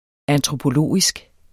Udtale [ antʁopoˈloˀisg ]